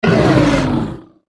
gnoll_commander_die.wav